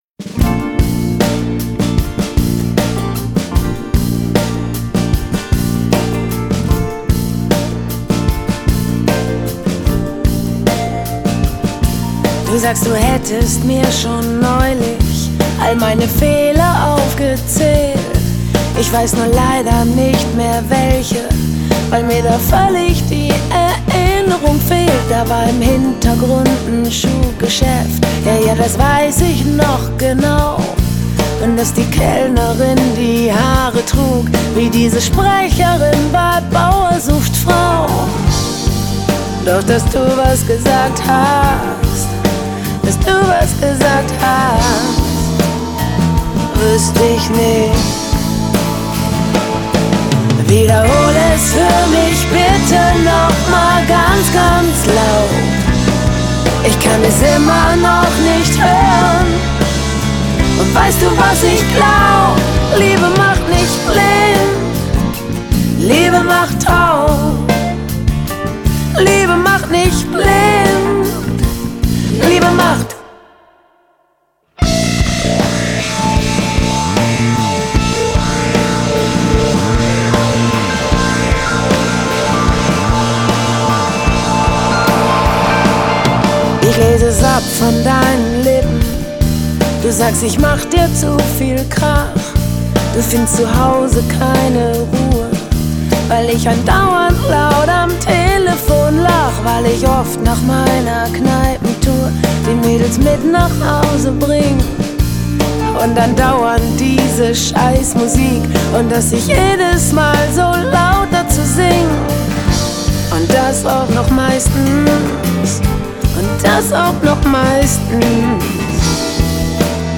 德国性感女声